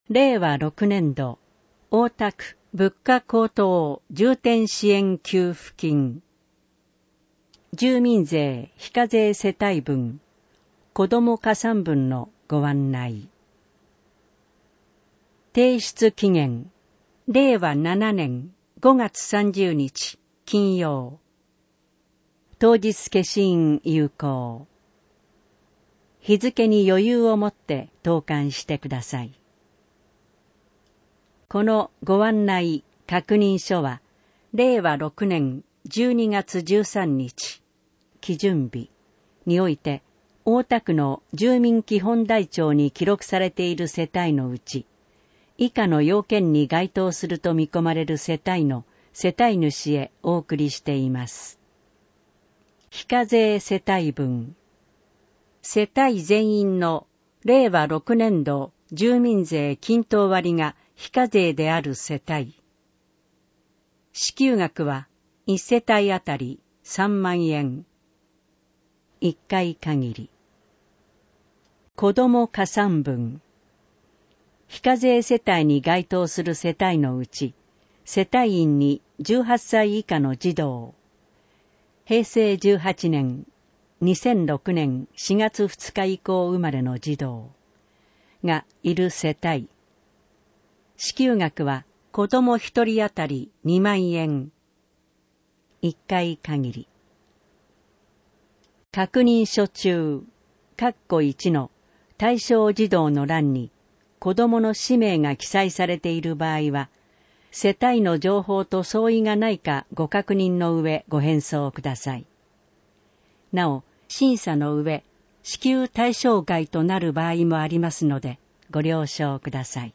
なお、この音声版は、障がい者総合サポートセンター声の図書室で製作したCDを再生したものです。